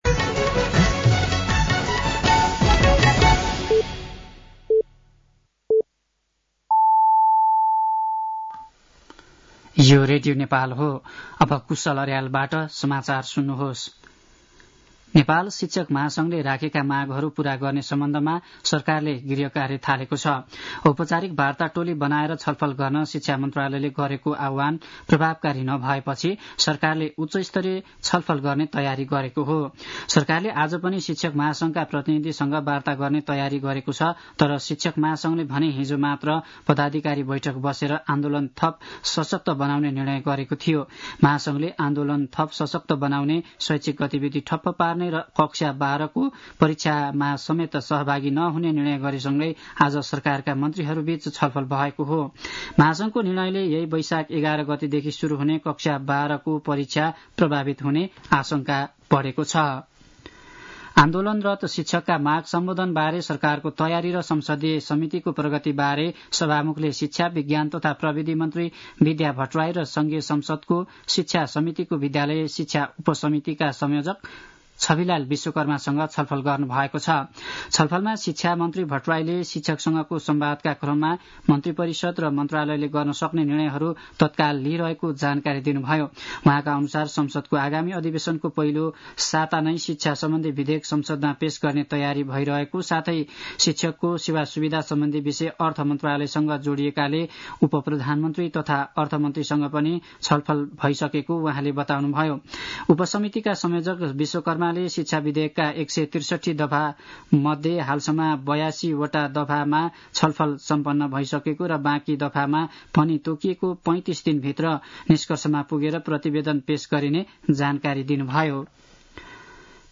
साँझ ५ बजेको नेपाली समाचार : २ वैशाख , २०८२